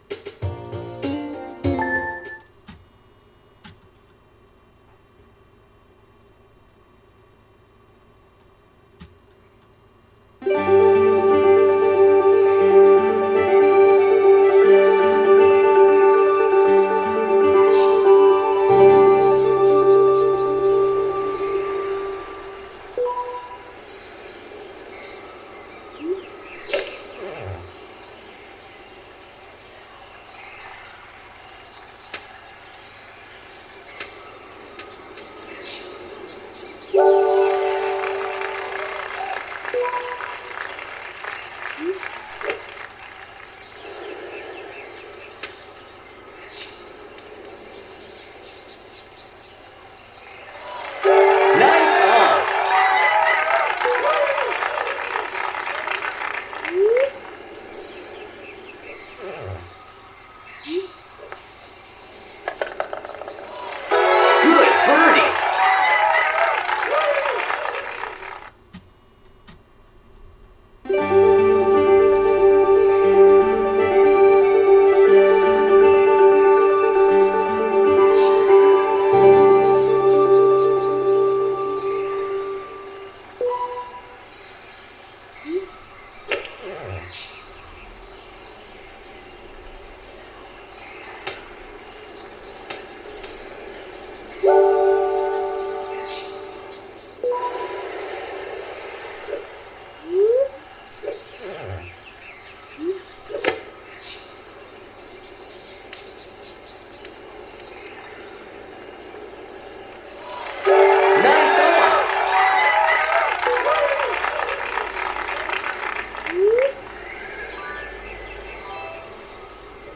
Per tutti gli ostacoli i suoni sono riprodotti alla perfezione: se cade in acqua o atterra in un bunker, oltre al fatto che viene anche emesso un suono di dissenso di una corda di chitarra.
Ho proposto anche il suono del Green quando la palla è nei pressi della bandierina.